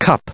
cup_en-us_recite_stardict.mp3